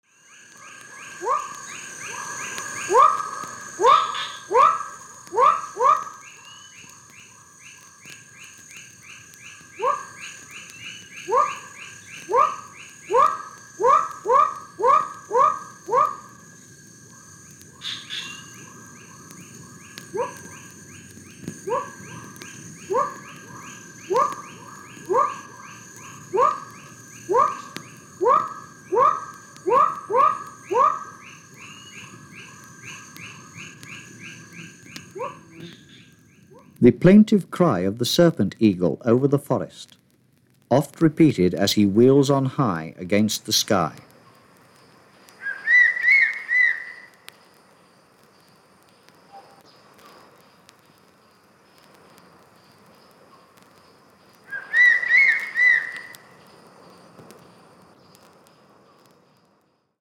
マレーシアのジャングルでフィールド録音されたレコードです。
何千年も前から変わらない鳥たちの鳴き声を淡々と説明しながら記録した作品です。